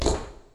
Pow.wav